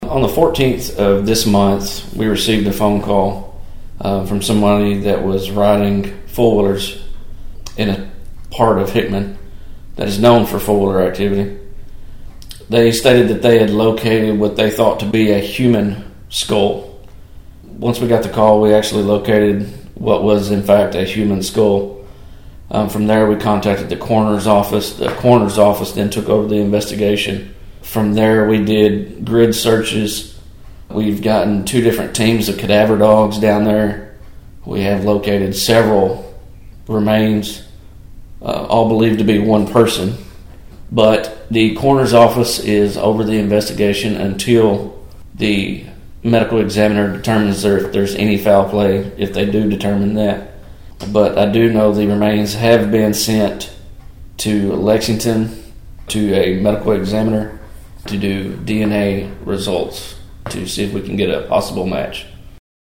Police Chief Ryan Amberg told Thunderbolt News about the initial call of the discovery.(AUDIO)